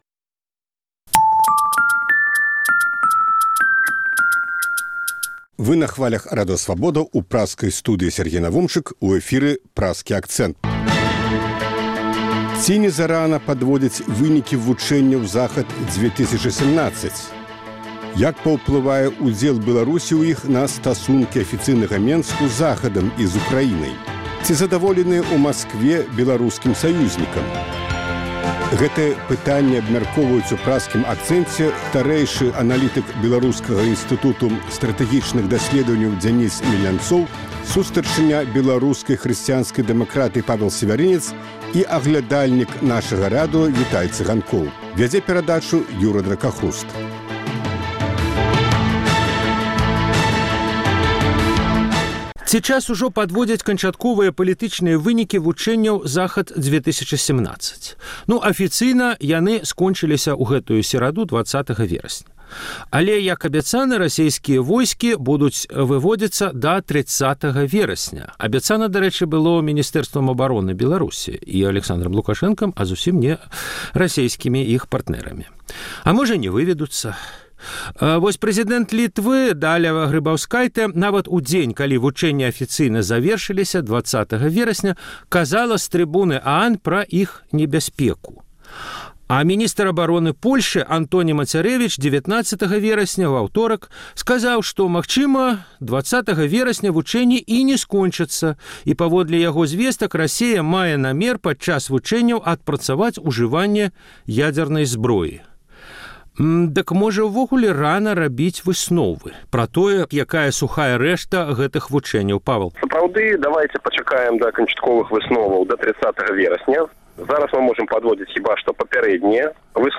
Як паўплывае ўдзел Беларусі ў іх на стасункі афіцыйнага Менску з Захадам і Ўкраінай? Ці задаволеныя ў Маскве беларускім саюзьнікам? Гэтыя пытаньні абмяркоўваюць у Праскім акцэнце журналісты